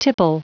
Prononciation du mot tipple en anglais (fichier audio)
Prononciation du mot : tipple